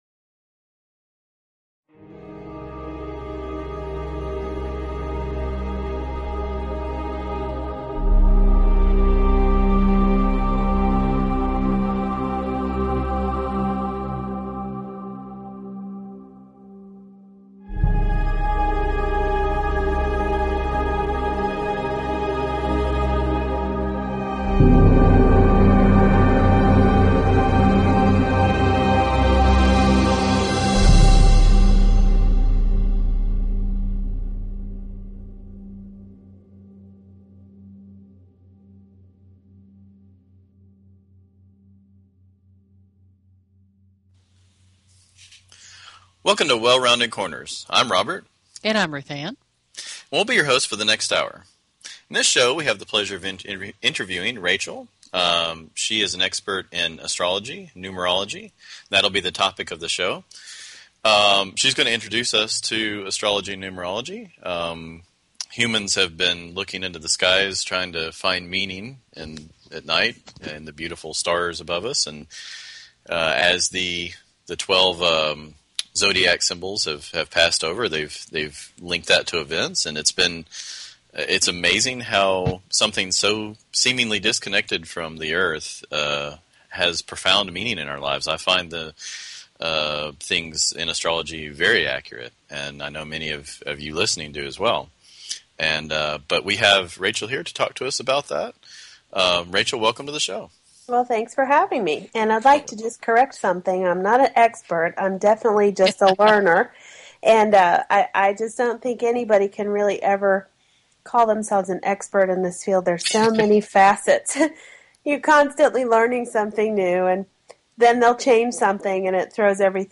Talk Show Episode, Audio Podcast, Well_Rounded_Corners and Courtesy of BBS Radio on , show guests , about , categorized as